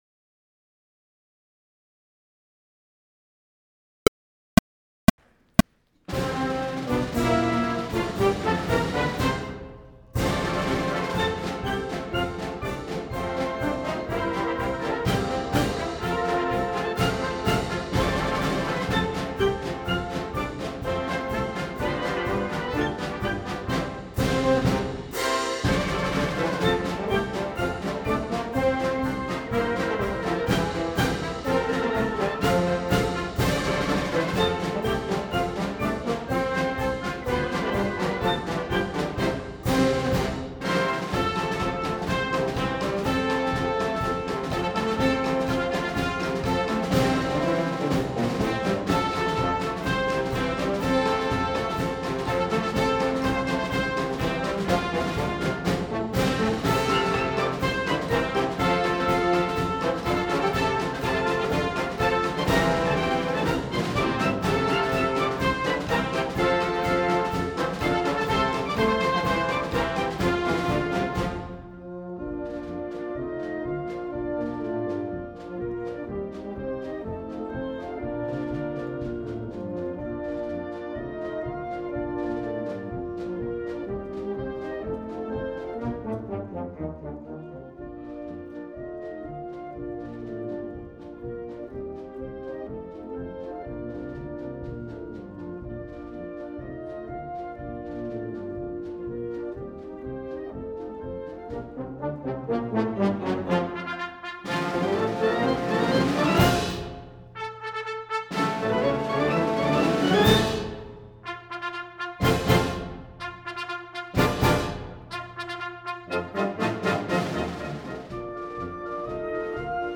- Follow the recording, starting after the four prep clicks.
Reference Recording (click track): The Thunderer